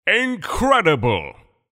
voice_tier7_incredible.mp3